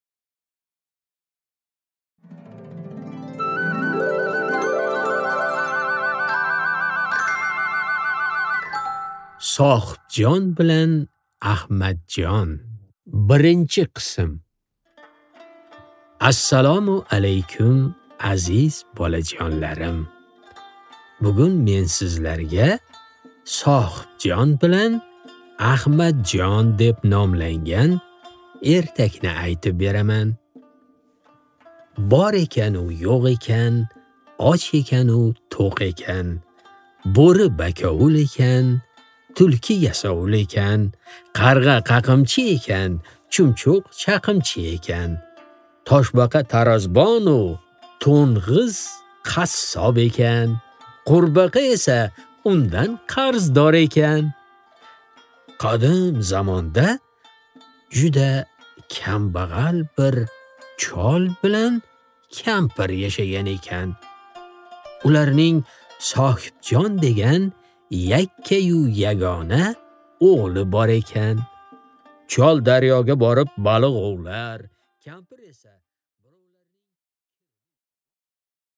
Аудиокнига Sohibjon bilan Ahmadjon